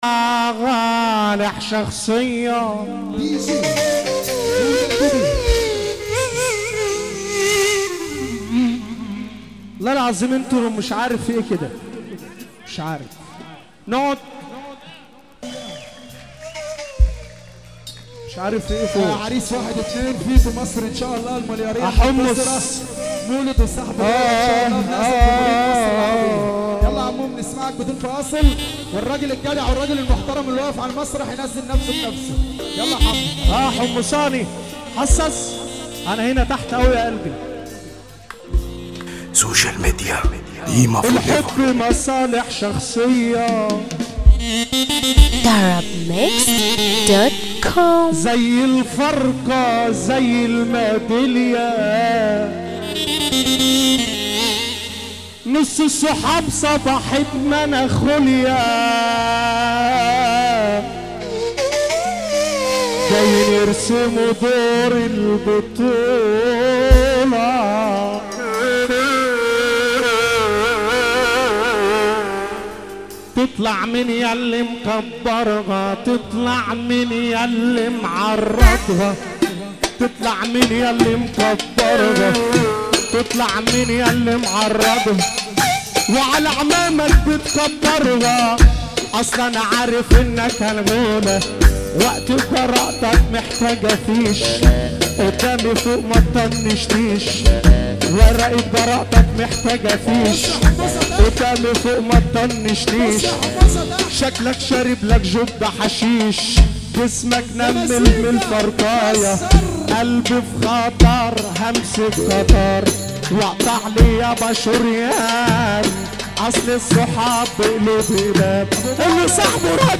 موال
حزينة